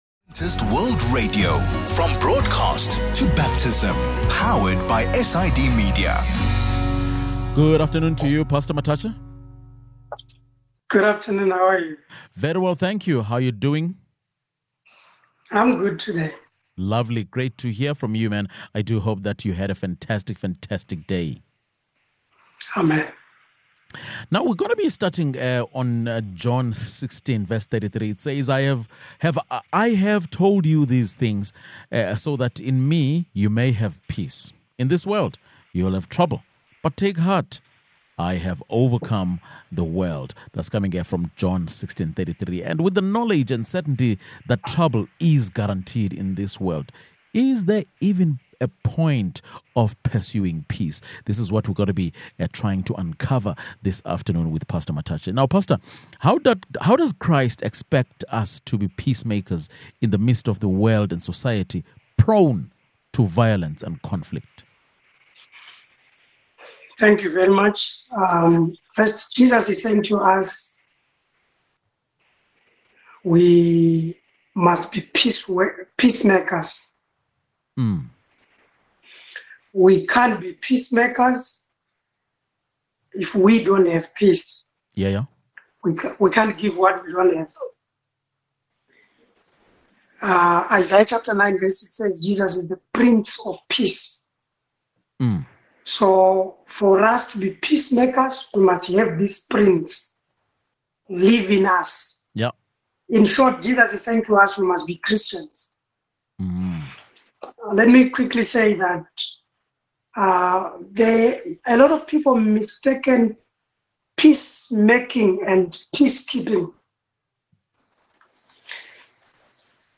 In this introductory conversation, we lay down the basic definitions of peace, quiet, and conflict. Can ‘peace’ and ‘quiet’ be used interchangeably?